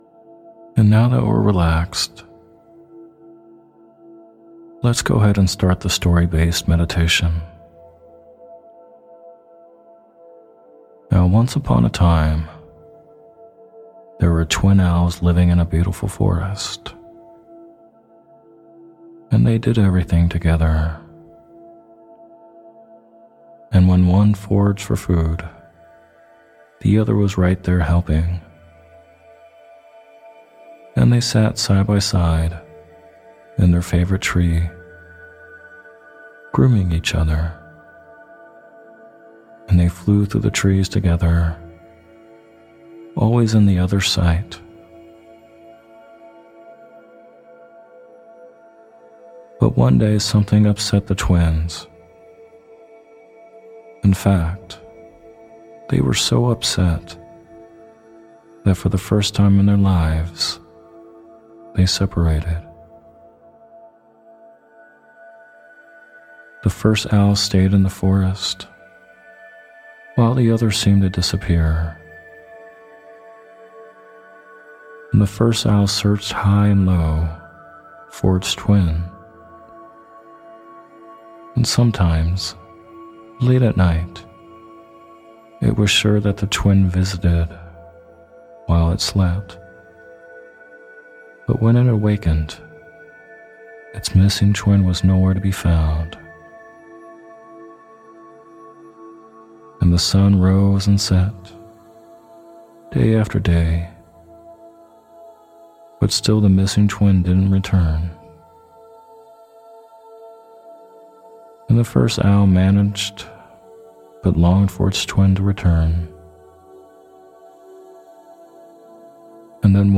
Story Based Meditation "The Two Owls or Twin Owls"
In this guided meditation called “The Two Owls” you’ll be told a story about twin owls that get separated. The metaphor will help you cultivate gratitude and to enjoy the journey verses a destination.